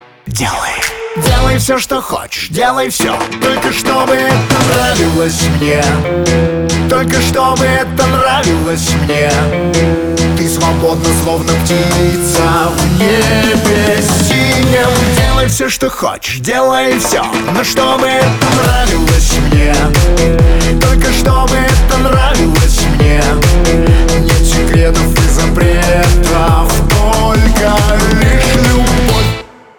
поп
гитара